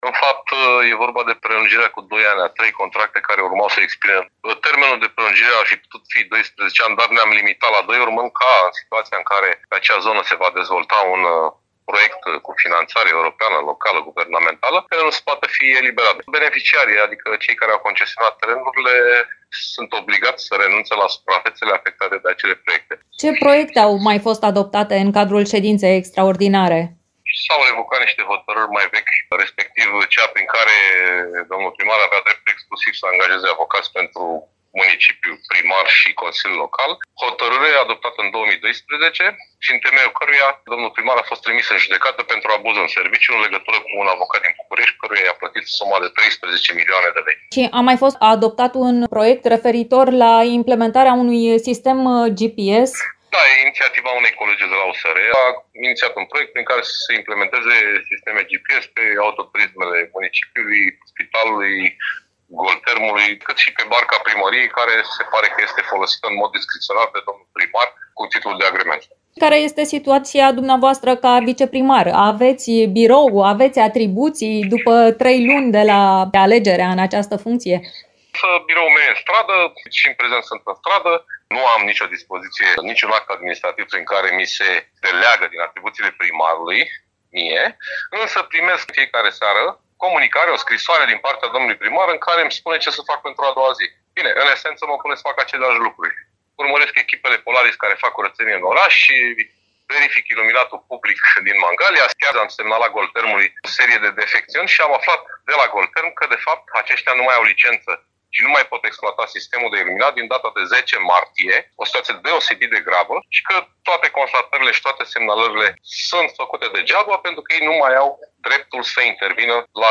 Detalii despre proiecte, dar și despre situația de la Primăria Mangalia ne-a oferit viceprimarul Paul Foleanu: